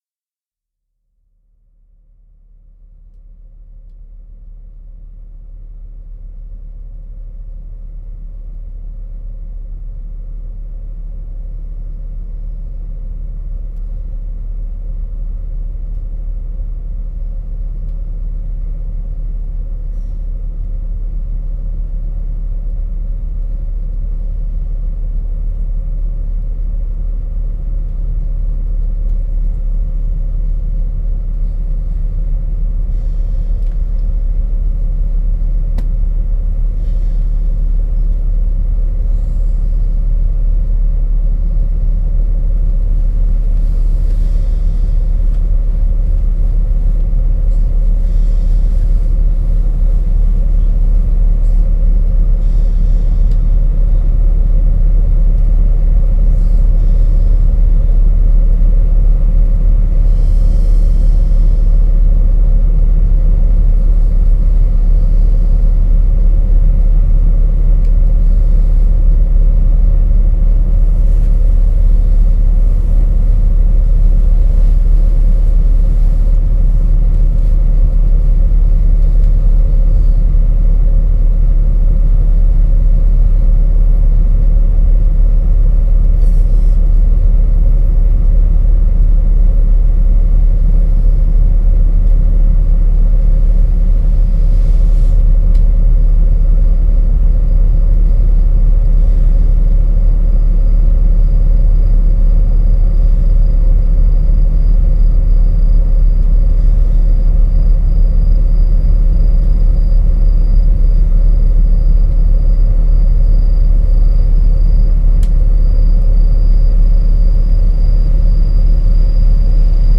TransMongolian is a composition of unprocessed field recordings composed in soundscapes.
Portrait 6 (Recorded in Japan, 10/2010)
And this starts with a amassed sound of arcade game machine melodies, cheesy lift music & darts of dance music ect…
Around the three minute mark these sounds fade, and a churning/ buffeting texture grows- it sounds like it could be recorded in the back of a small boat that’s chugging along a quiet waterway. By the 5th minute distant bird type chatter has been added along with the sound of a passing train, before we move into the slowly growing sound of approaching footsteps.